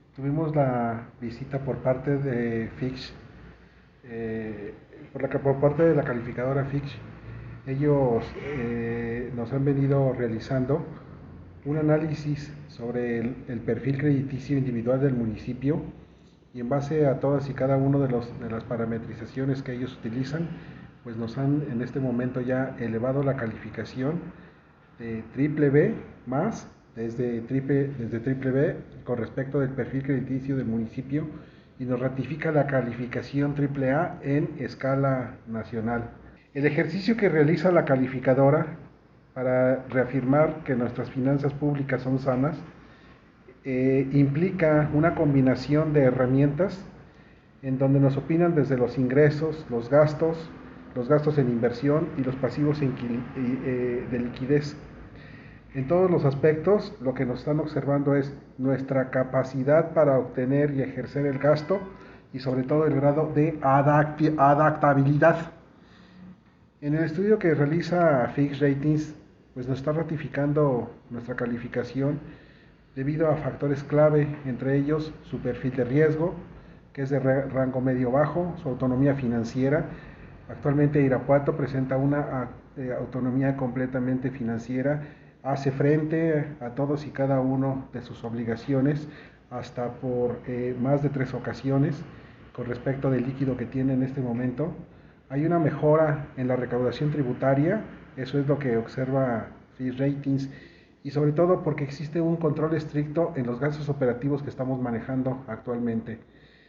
AudioBoletines
Miguel Ángel Fonseca Gutiérrez, tesorero municipal